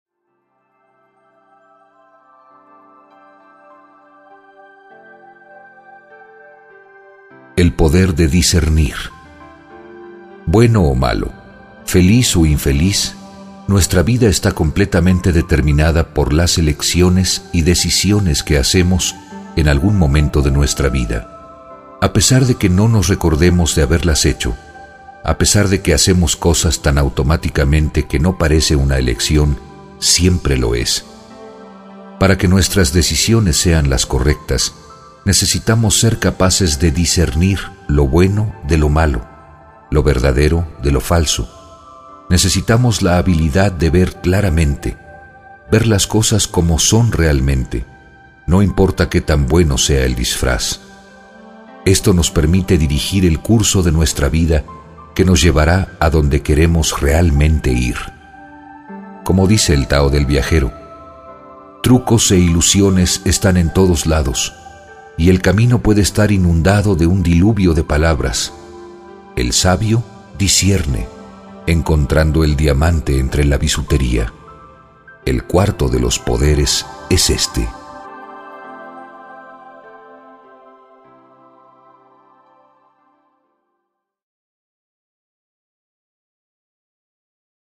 Genre Meditaciones Guiadas
meditaciones-guiadas